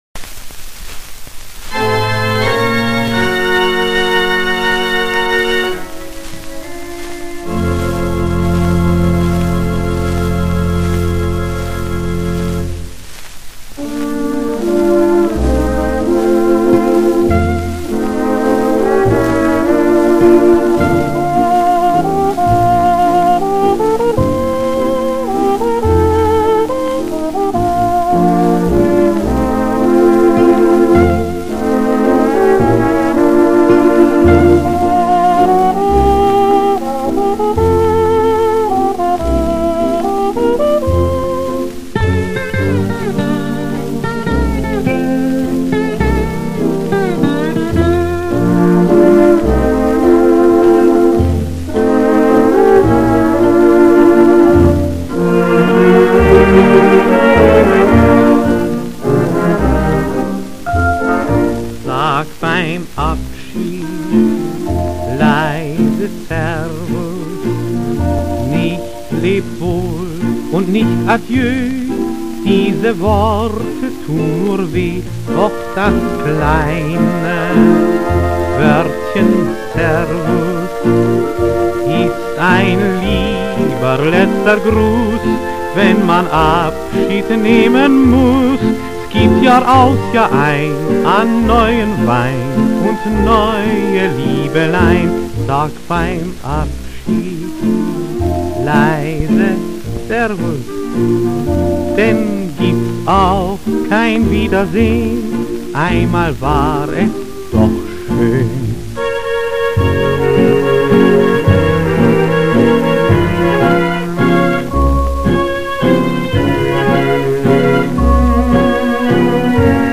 Tanz-Kapelle